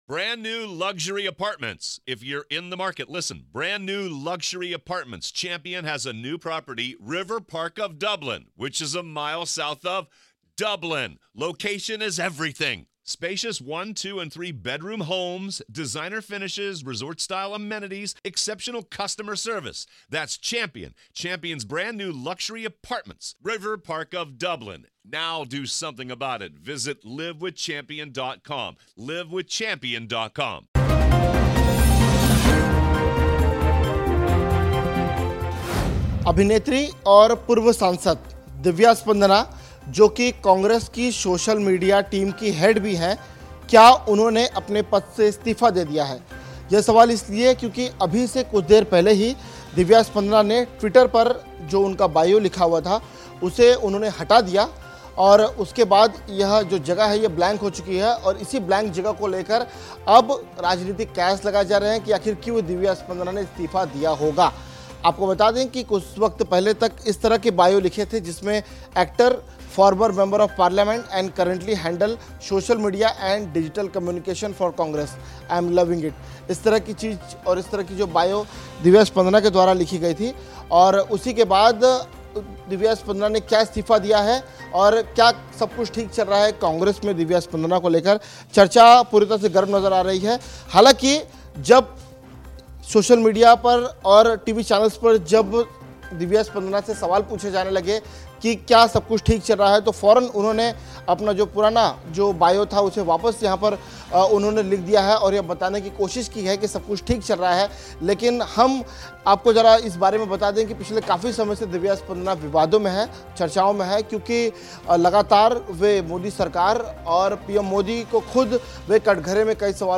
न्यूज़ रिपोर्ट - News Report Hindi / दिव्या स्पंदना ने दिया कांग्रेस के सोशल मीडिया हेड के पद से इस्तीफा?